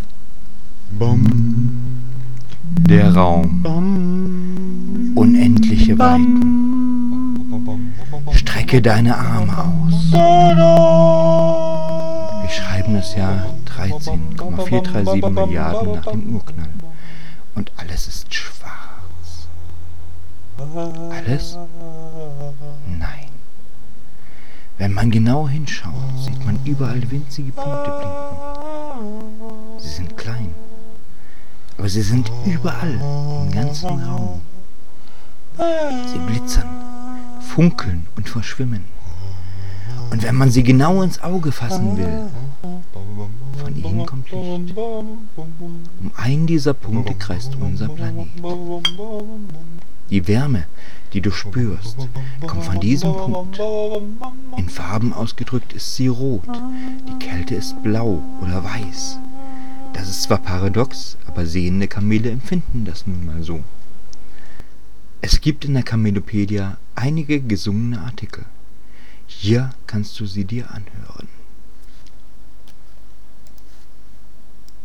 Es gibt in der Kamelopedia einige gesungene Artikel, hier kannst Du sie Dir anhören: Kamelopedia:Gesungene Kamelopedia
Lautsprecher trans.png   Dieser Beitrag existiert im Rahmen des Projekts Gesungene Kamelopedia auch als Audiodatei.